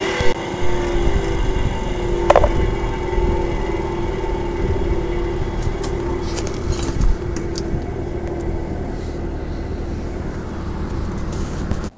Bruit unité externe Daikin PAC air eau
Depuis quelques jours, toutes les 30 minutes l'unité externe de ma PAC Air/Eau Daikin (ERHQ011AAV3 16kW) émet un bruit métallique grave pendant 10-15 sec, puis le compresseur s'arrête (fichier MP3 ci dessous).
Oui gros bruit de "frottement" ?
La décélération me semble longue pour être celle d'un compresseur (si toutefois il y a du gaz dans le système).